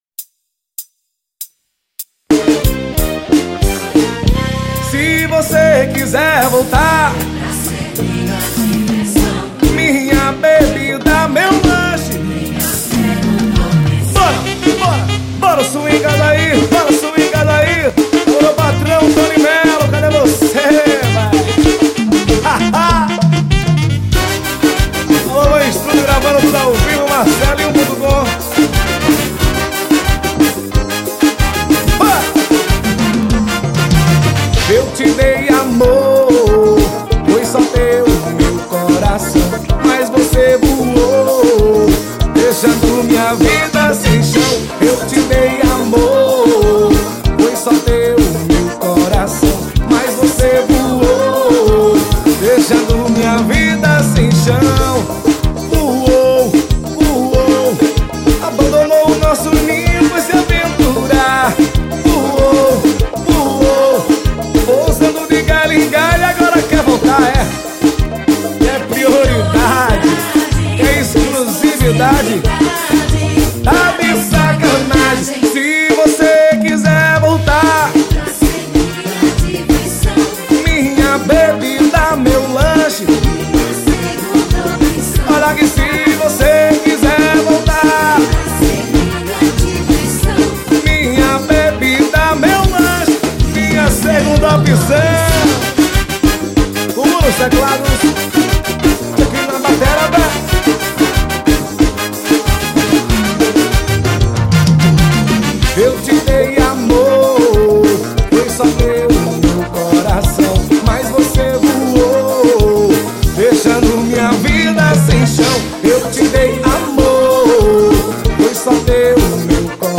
Composição: forró.